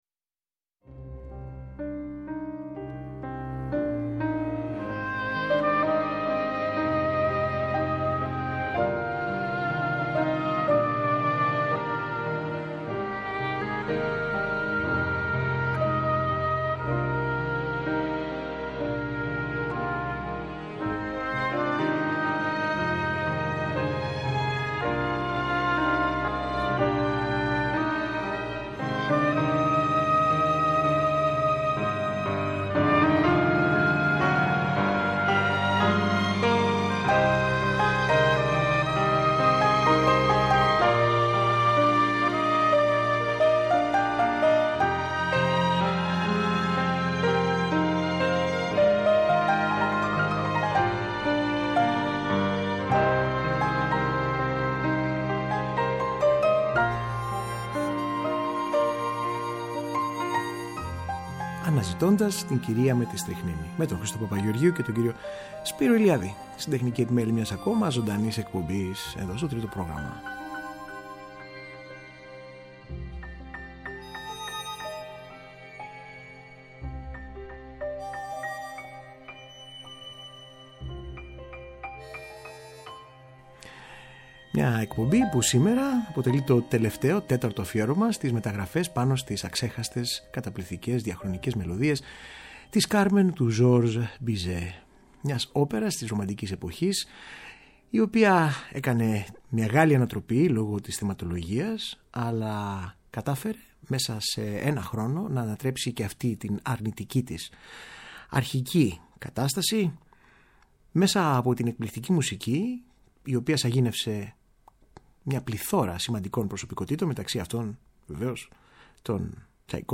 Οι απίστευτα ευφάνταστες και ποικίλες εκδοχές των μελωδιών της πασίγνωστης δημοφιλούς όπερας από την κλασική μέχρι την τζαζ και την ροκ.